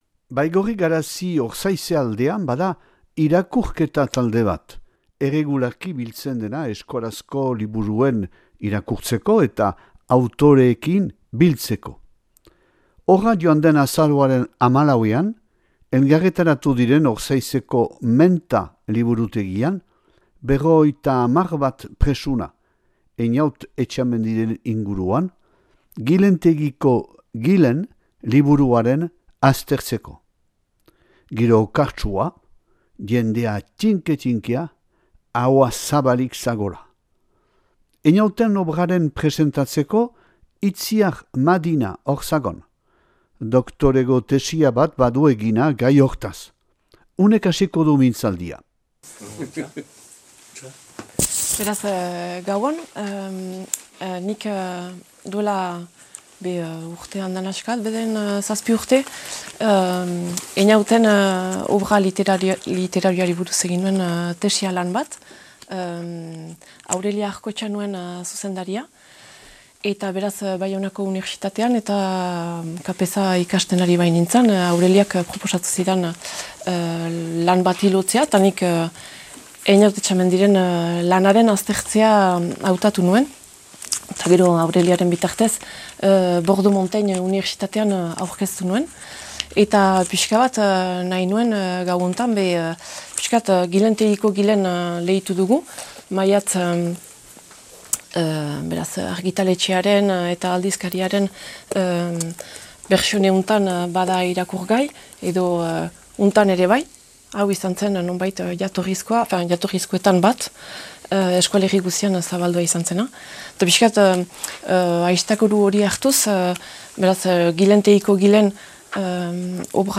Aldude Baigorri Ortzaizeko irakurle taldeak antolaturik Ortzaizeko Menta liburutegian 2024. azaroaren 14an.